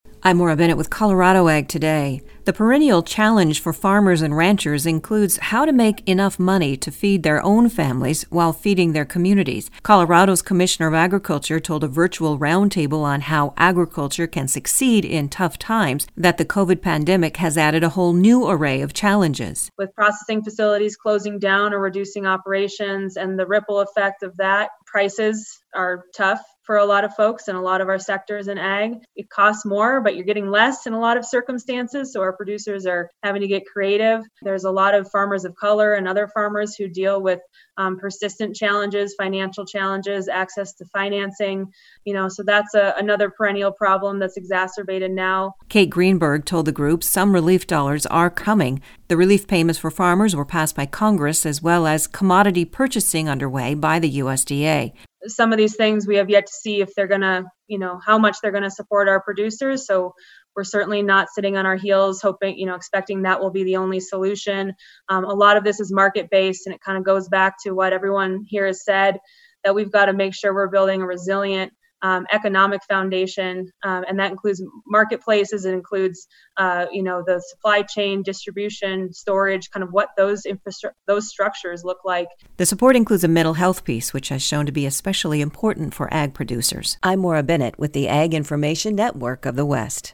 Colorado’s Commissioner of Agriculture told a virtual roundtable on How Agriculture Can Succeed in Tough Times that COVID has added a whole new array of challenges.